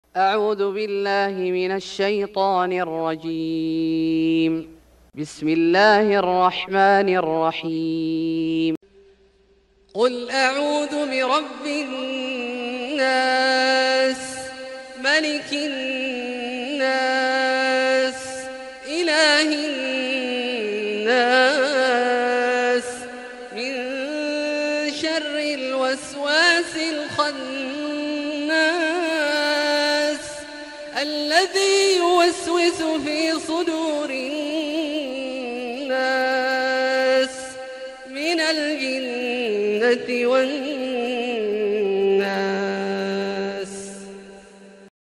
سورة الناس Surat An-Nas > مصحف الشيخ عبدالله الجهني من الحرم المكي > المصحف - تلاوات الحرمين